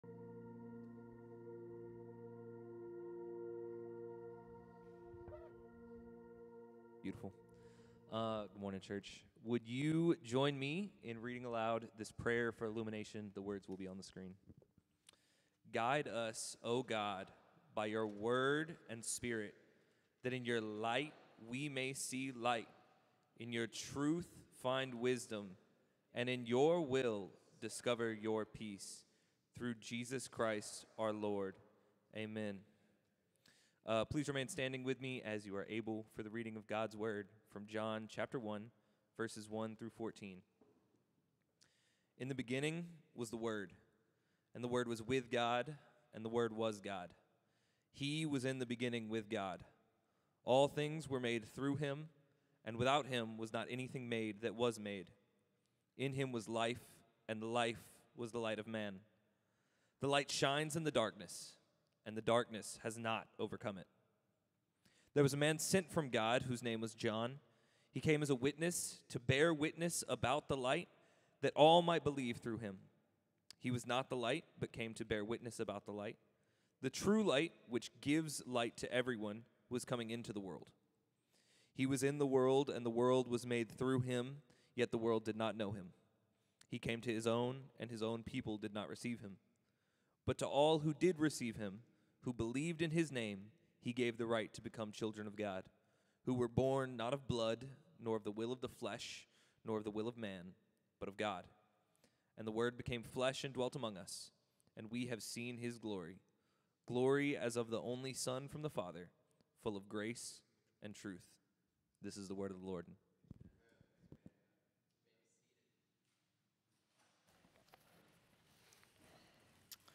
The Source of Real Life | John 1:1–14 Sermon